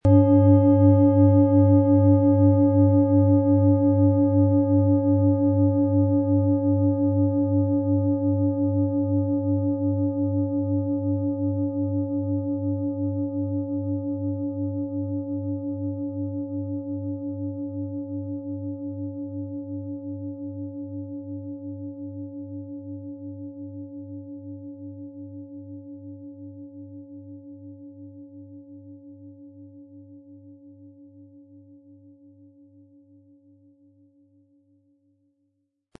Handgearbeitete tibetische Schale mit dem Planetenton Tageston.
• Mittlerer Ton: Merkur
Im Sound-Player - Jetzt reinhören können Sie den Original-Ton genau dieser Schale anhören.
PlanetentöneTageston & Merkur & DNA (Höchster Ton)
MaterialBronze